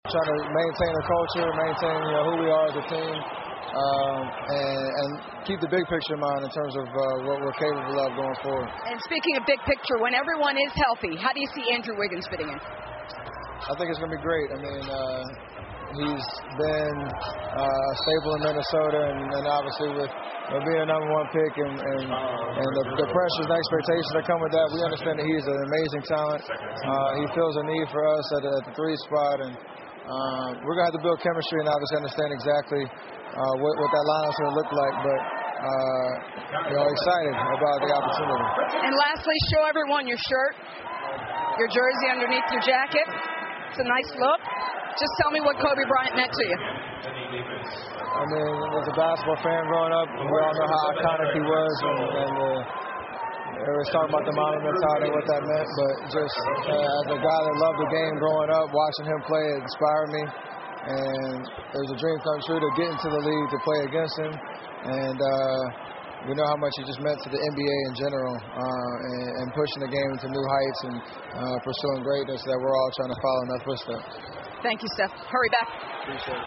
篮球英文堂 第254期:库里穿着科比球衣最新采访(2) 听力文件下载—在线英语听力室